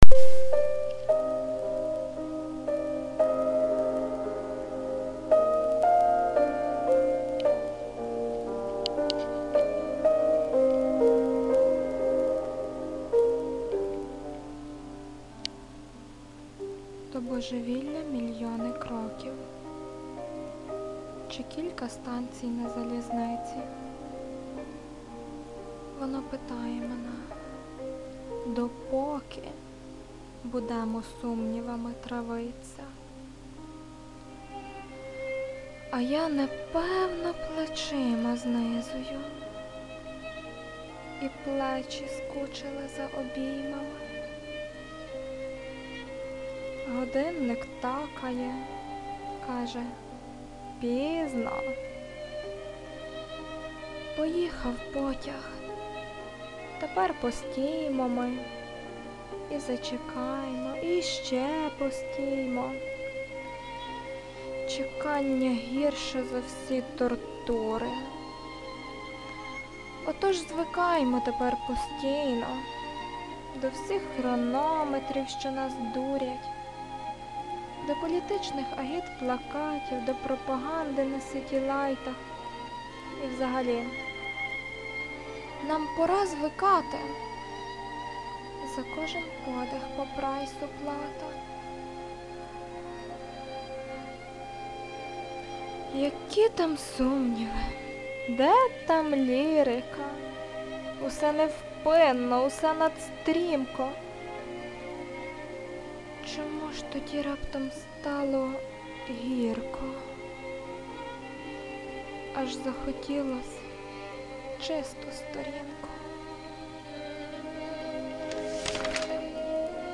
божевілля (озвучене)
Цікаво Ви читаєте цей доволі сумний текст.
Сподобалось завершення - останні звуки, коли перегортали сторінки, шукаючи бажаної - чистої...
БРАВО АВТОРУ...ЦЕ ЙОМУ ВДАЄТЬСЯ ПОЄДНУВАТИ ПСИХОЛОГІЮ ГЕРОЯ ТА ЛІРИКУ МУЗИЧНОГО СМУТКУ 12